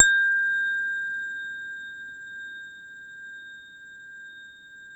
WHINE  A#4-R.wav